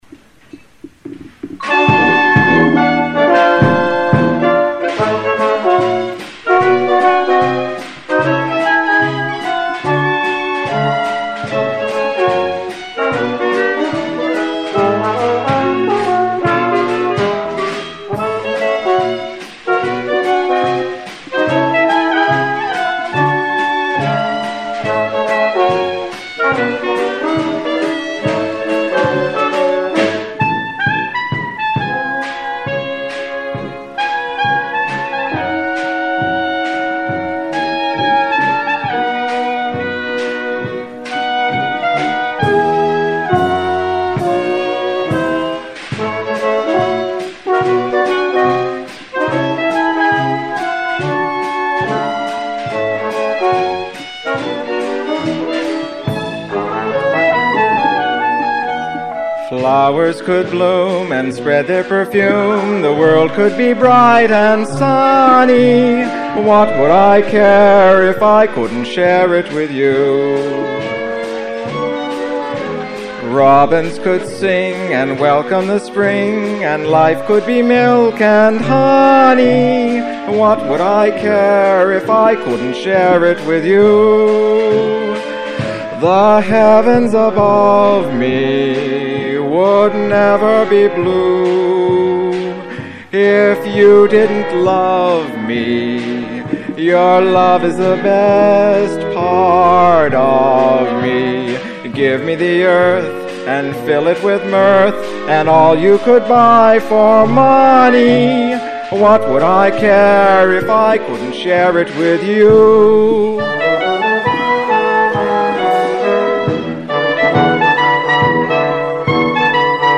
What Would I Care?Voice, instrumental ensemble